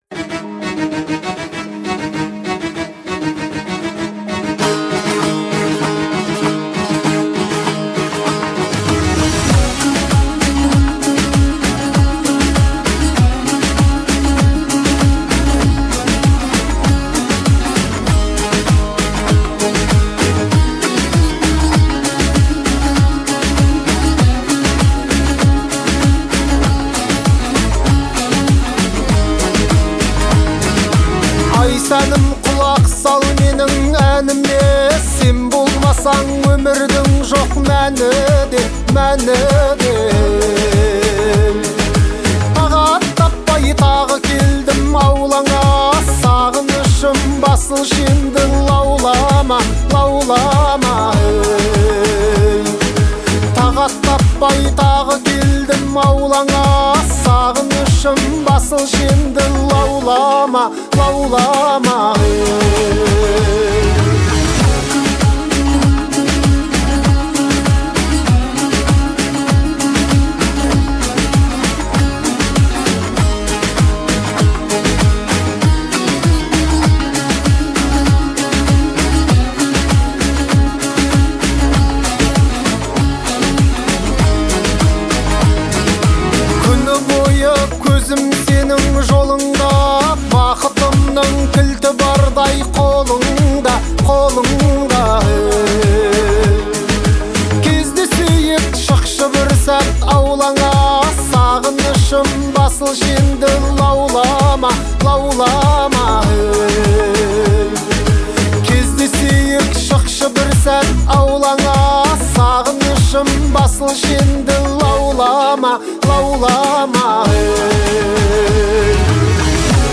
казахским певцом